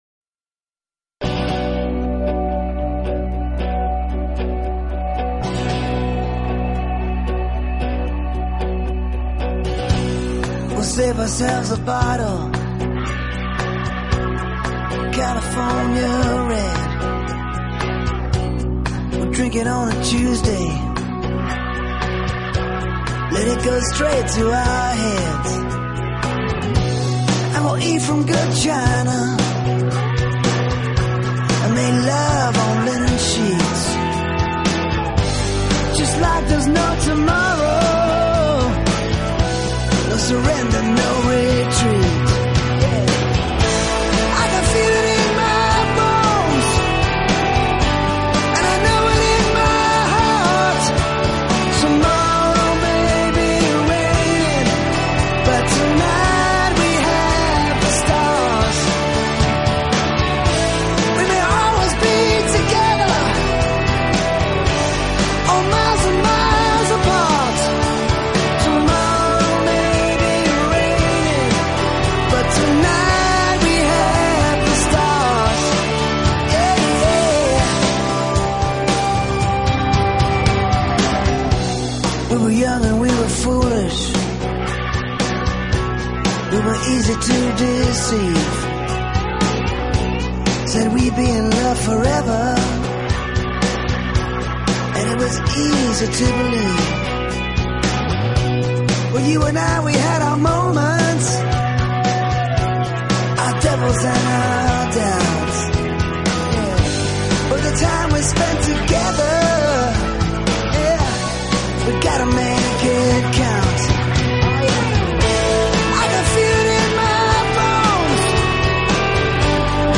【欧美歌曲】
多样的关系，并且大量使用简单的吉他伴奏，营造着慢条斯理的氛围，并且